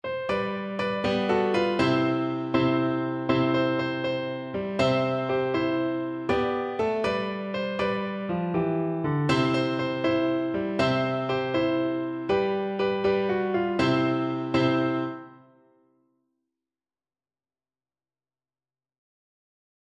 Childrens Childrens Flute Sheet Music Here we go round the Mulberry Bush
Flute
Joyfully .=c.80
6/8 (View more 6/8 Music)
C major (Sounding Pitch) (View more C major Music for Flute )